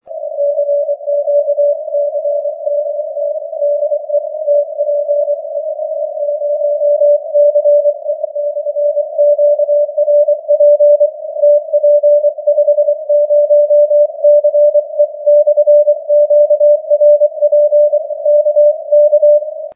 Диапазон у меня сейчас очень шумный, сигналы вытаскивал "коунтером" и заужением полосы временами до 100 Гц.
Сейчас на частоте хороший пайлап собрался!